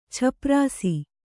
♪ chaprāsi